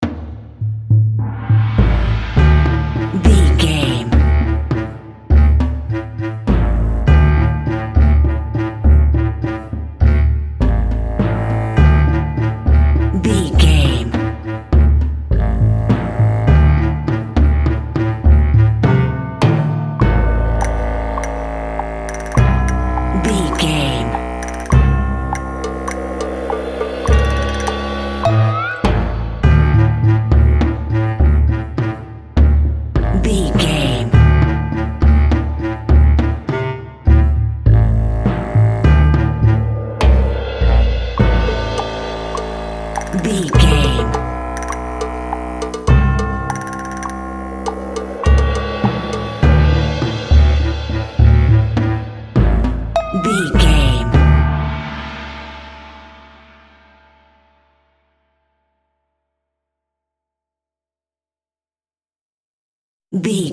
Aeolian/Minor
D
strings
horns
percussion
silly
circus
goofy
comical
cheerful
perky
Light hearted
quirky